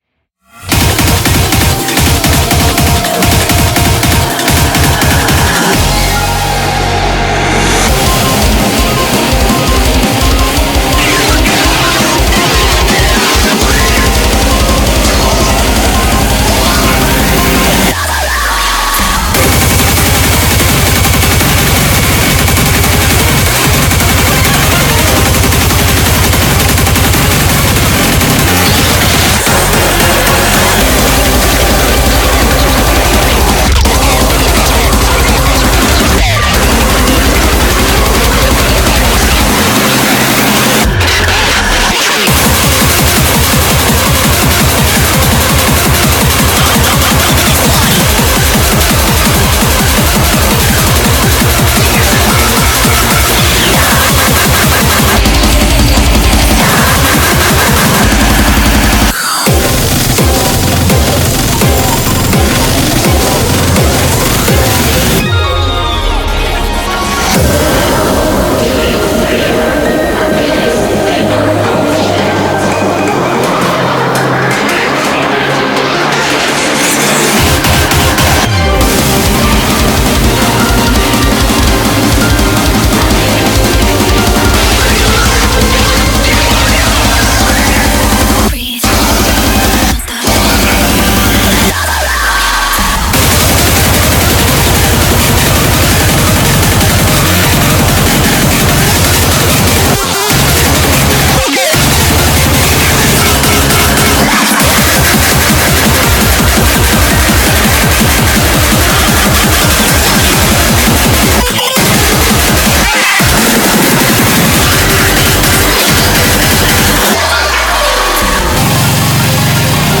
BPM168-335
Audio QualityMusic Cut
Genre: Speedcore